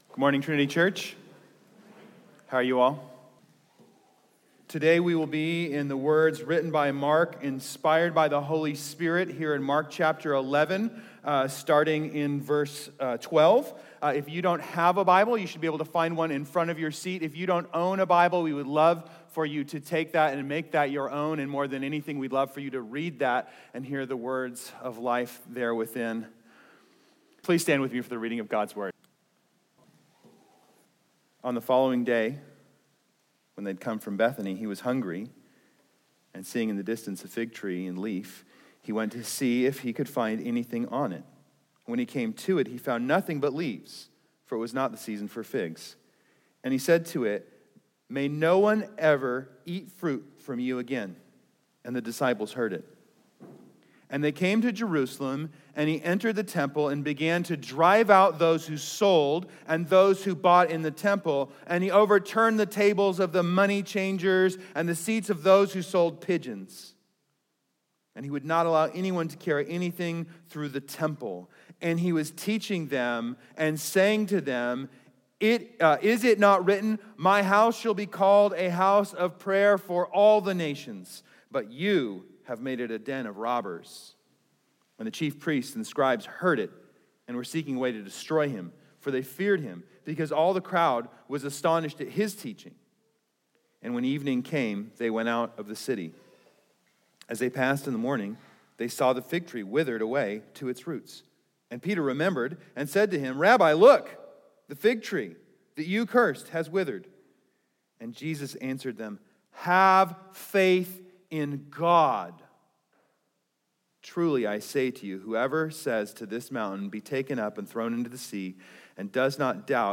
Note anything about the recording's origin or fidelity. Trinity Church Portland